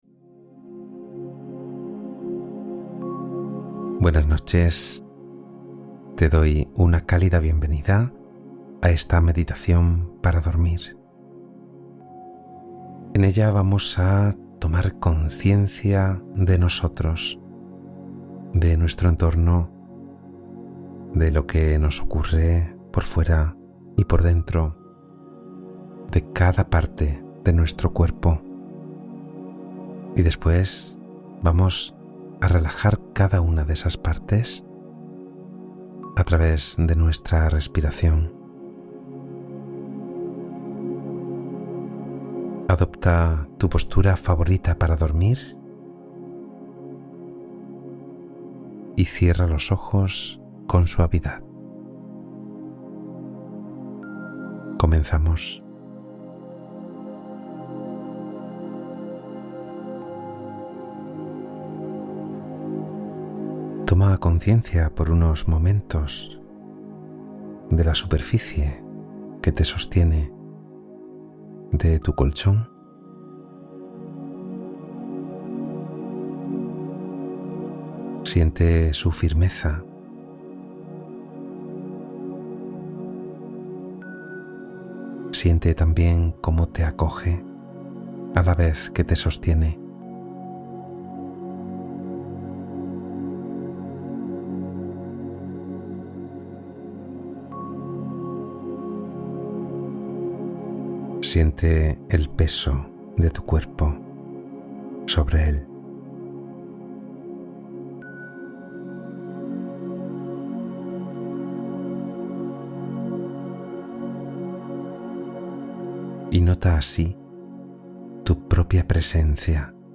Meditación mindfulness para dormir profundamente y restaurar energía